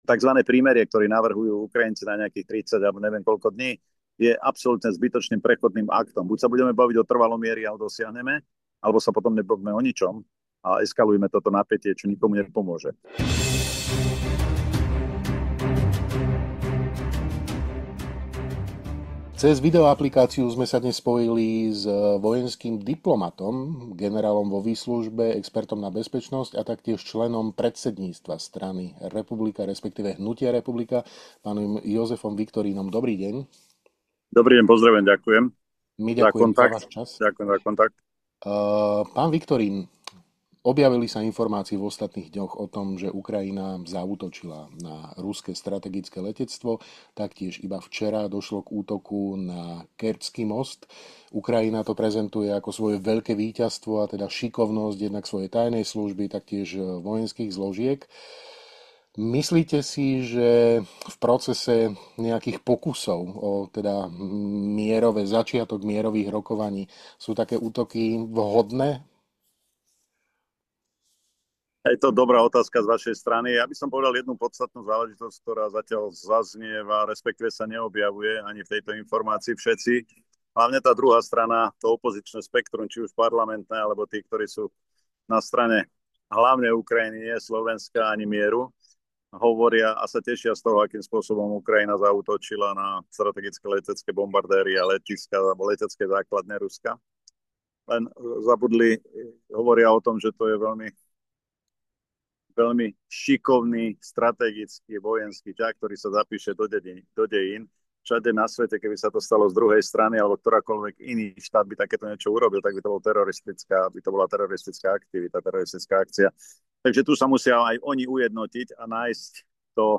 Rozprávali sme sa s odborníkom na vojenskú diplomaciu a bezpečnosť